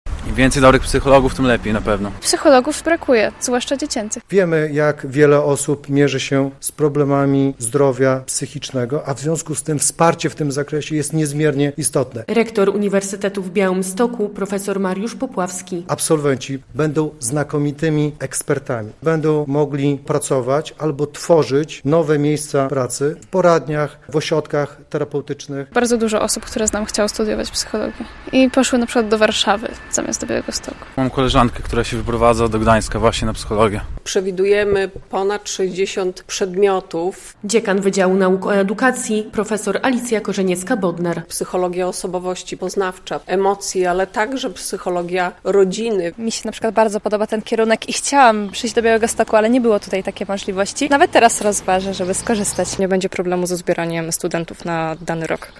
Na UwB powraca kierunek psychologia - relacja